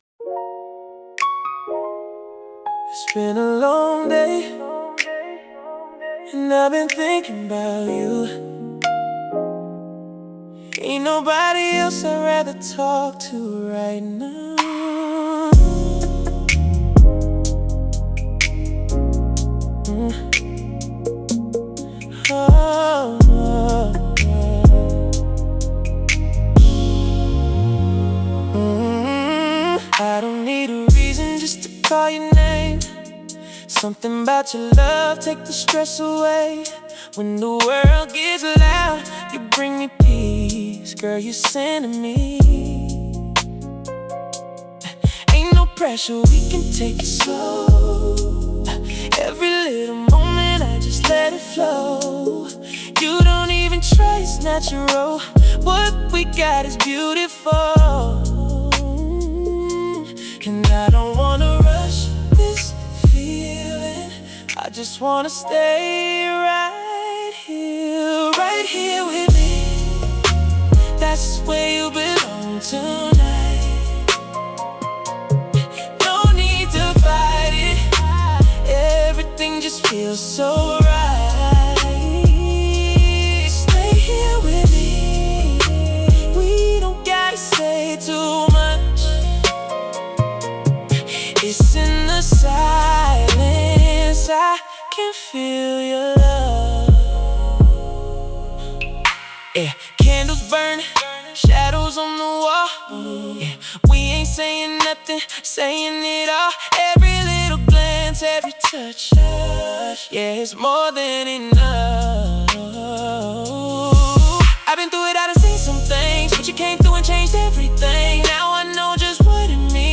Romantic | Devotional 80 BPM